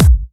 VEC3 Bassdrums Trance 65.wav